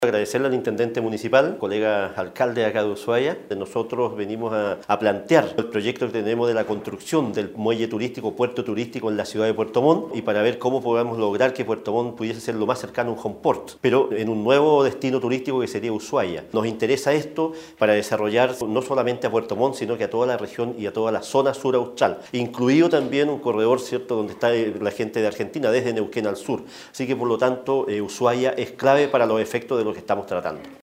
En la misma línea el alcalde de Puerto Montt, Gervoy Paredes, expresó su conformidad con la reunión sostenida, en donde se pudo conocer los importantes proyectos relacionado al turismo de cruceros, logrando el total apoyo de la ciudad de Ushuaia y sus autoridades, dando inicio a una agenda de trabajo en conjunto.